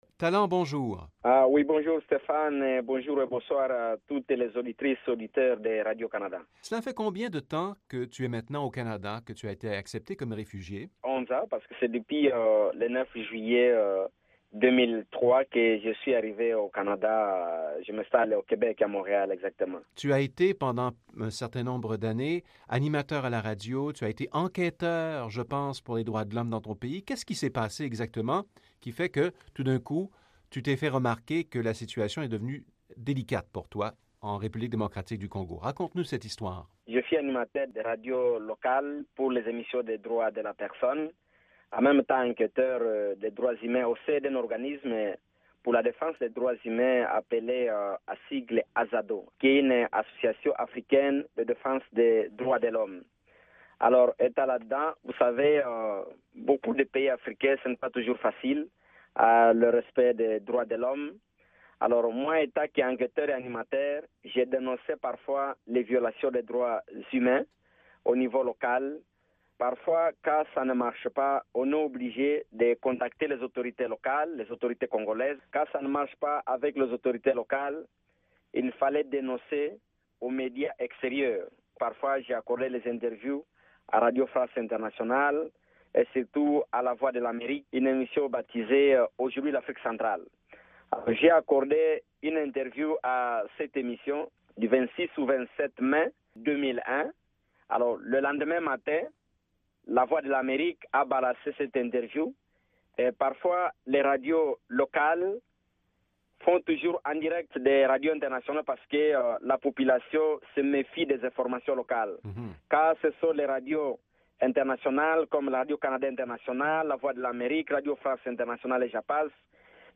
lors d'un passage dans les studios de Radio Canada International